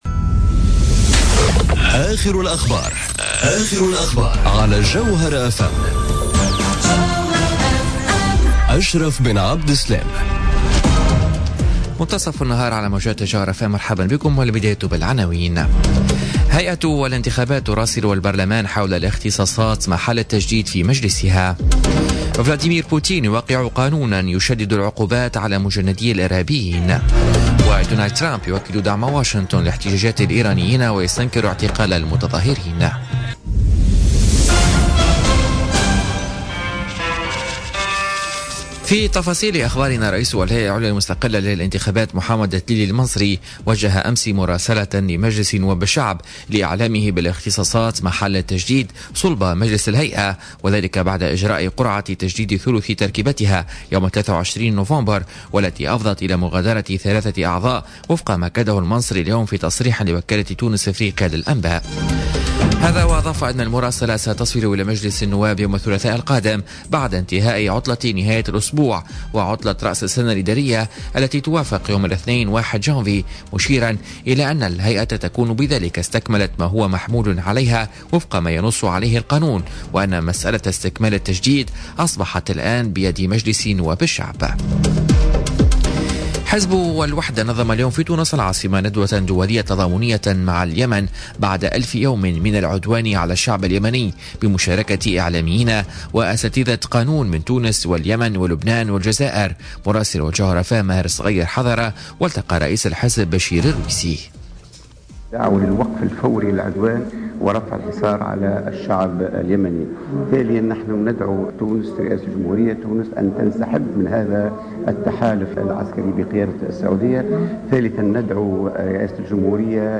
نشرة أخبار منتصف النهار ليوم السبت 30 ديسمبر 2017